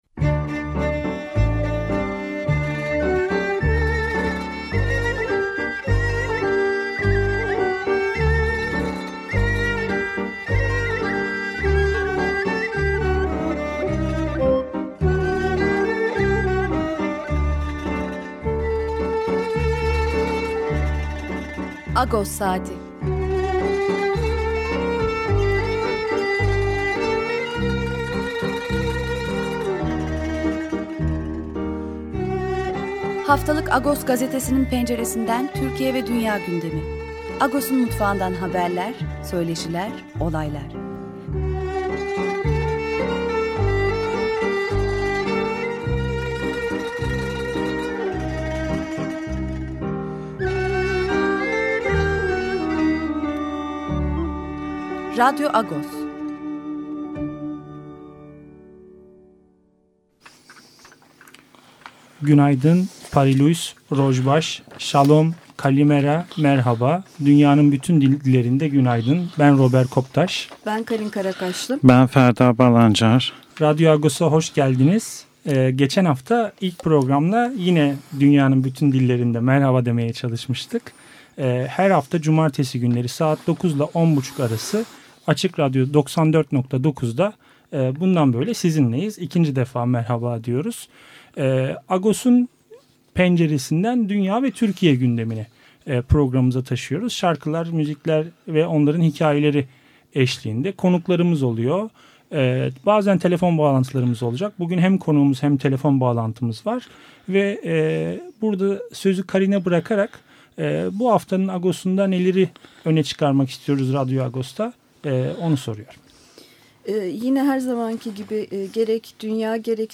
Her Cumartesi Açık Radyo'da 09.00 ve 10.30 saatleri arasında yayınlanan Agos Saati'ni kaçıranlar programı buradan dinleyebilir.